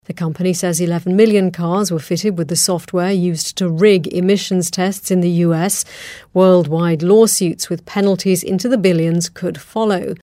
【英音模仿秀】大众总裁辞职 听力文件下载—在线英语听力室